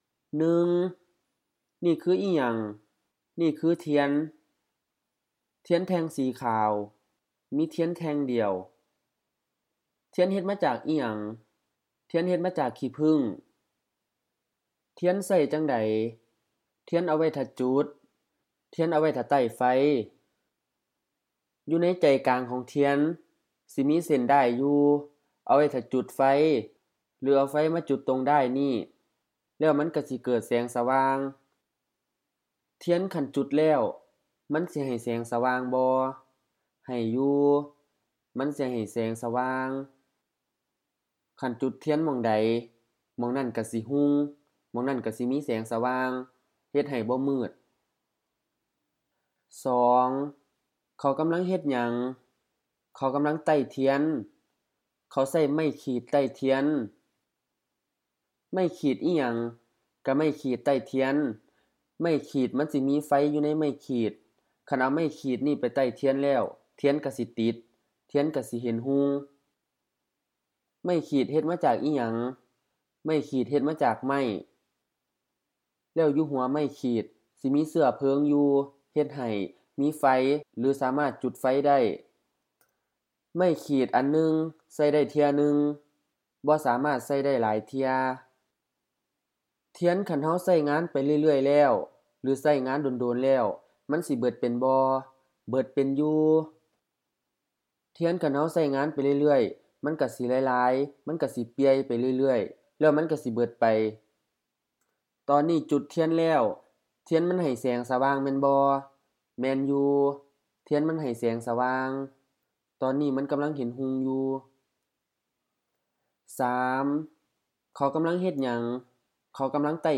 *should be LF-LF, probably interference from standard Thai